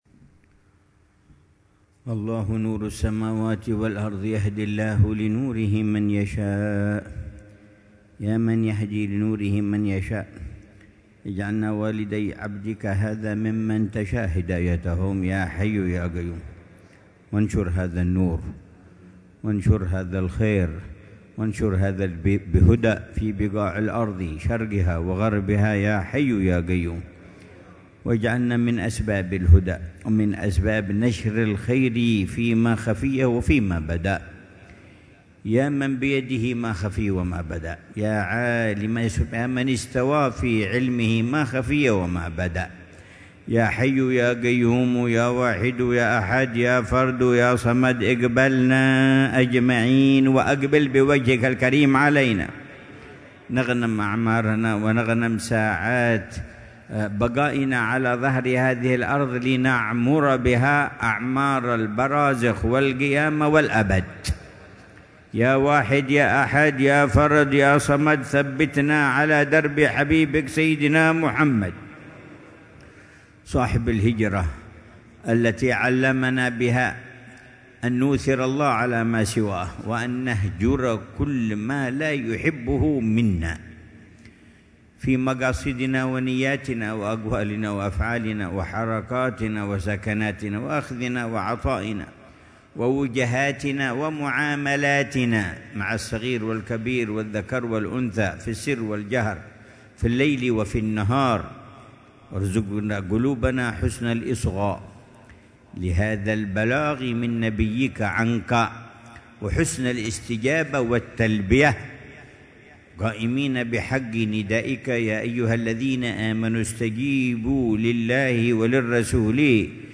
محاضرة العلامة الحبيب عمر بن محمد بن حفيظ ضمن سلسلة إرشادات السلوك، ليلة الجمعة 9 محرم 1447هـ في دار المصطفى بتريم، بعنوان: